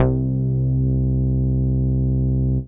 ARP BASS 2.wav